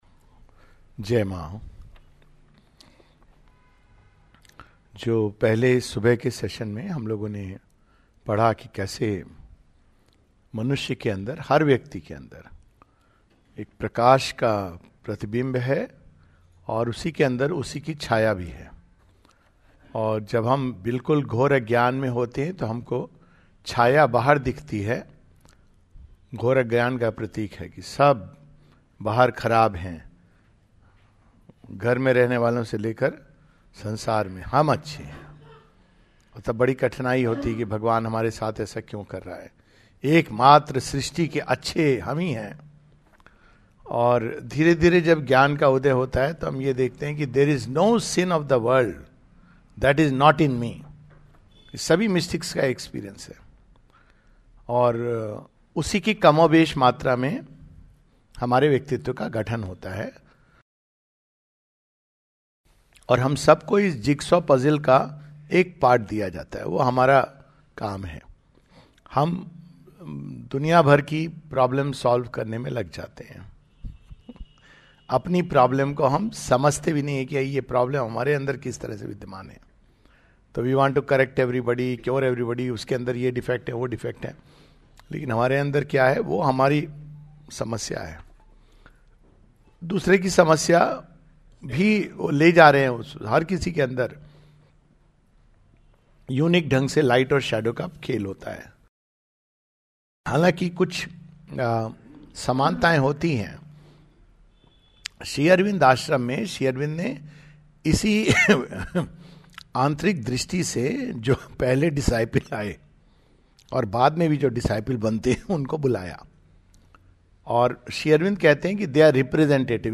(Cosmic Consciousness) A Few experiences of Sri Aurobindo. A talk
at Ratlam, MP